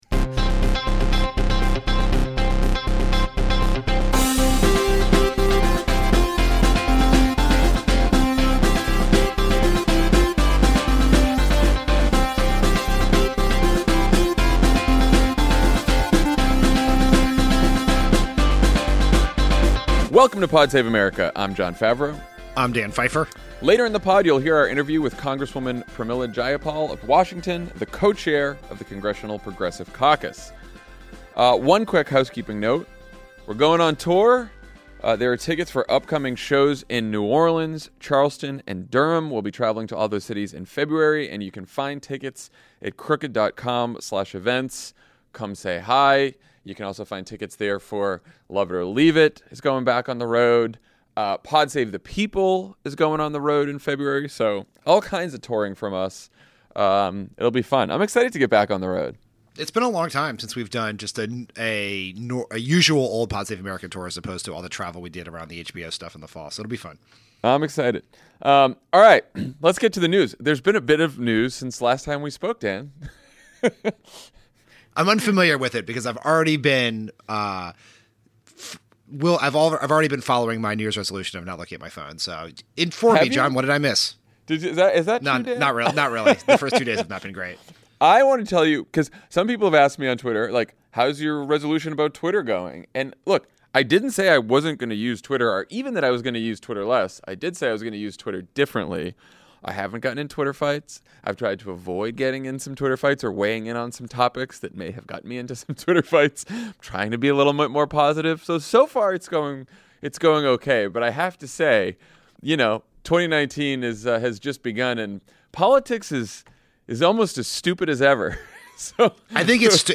Trump keeps the government shut down over his metaphorical wall, House Democrats bring back PAYGO, Elizabeth Warren kicks off her presidential campaign, and Mitt Romney writes a sternly-worded op-ed. Then Congresswoman Pramila Jayapal talks to Jon and Dan about the shutdown, immigration, and the priorities of the Congressional Progressive Caucus.